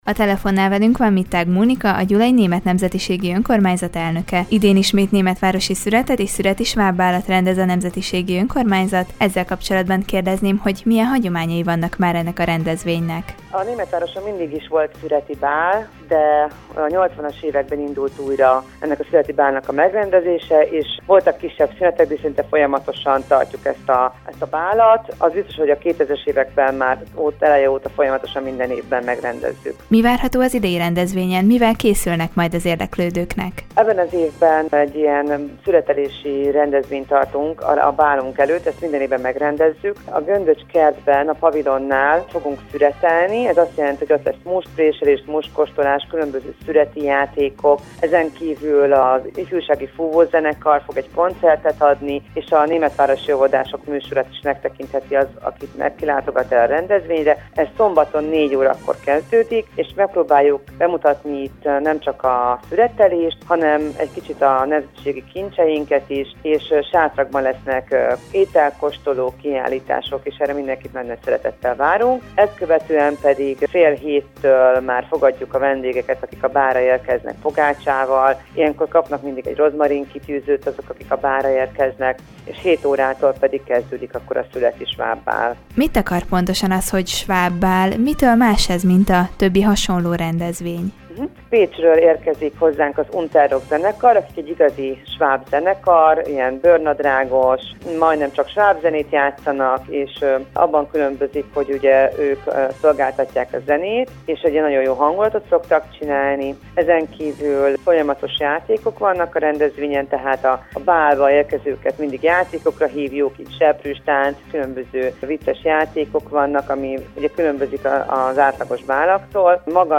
Ismét megrendezésre kerül a szüreti sváb bál Gyulán. A rendezvény programjairól beszélgetett a Körös Hírcentrum tudósítója Mittág Mónikával, Gyula város Német Nemzetiségi Önkormányzatának elnökével.